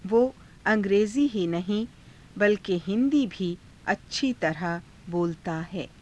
ゆっくり ふつう